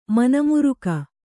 ♪ mana muruka